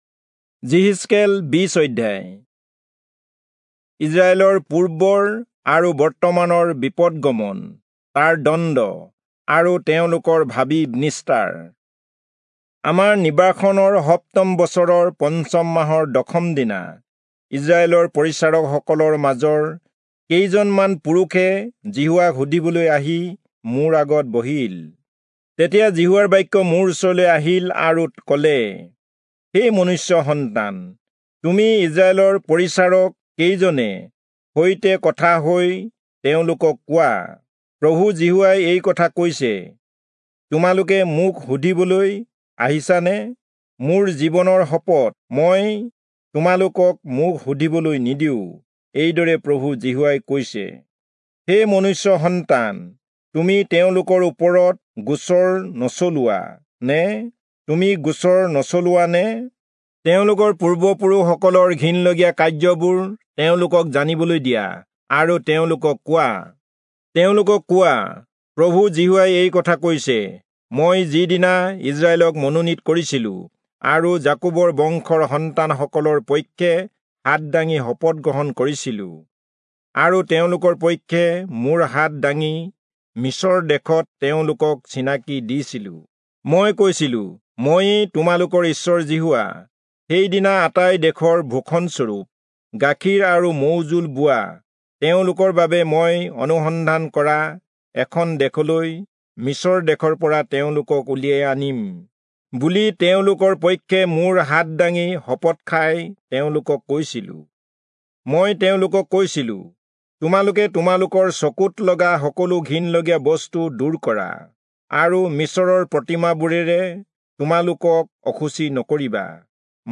Assamese Audio Bible - Ezekiel 45 in Irvmr bible version